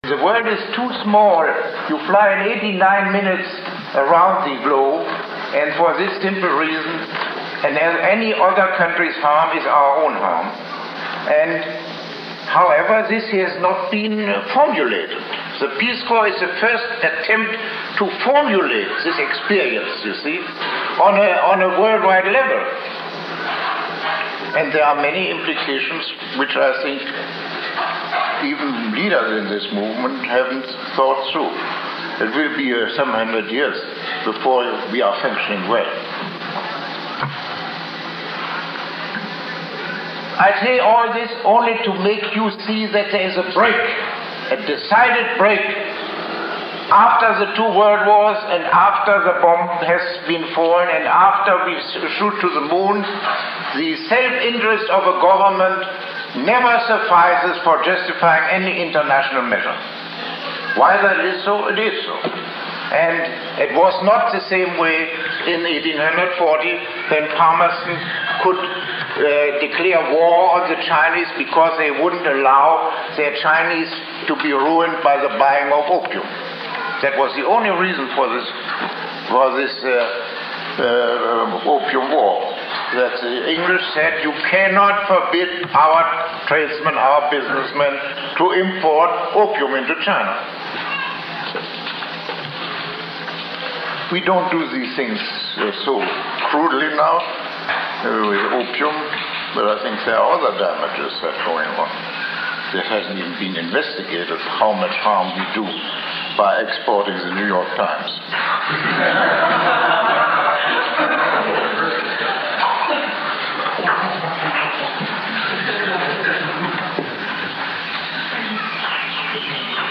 NOTE: This lecture is made up of excerpts from Peace Corps (1966), Lectures 1 and 2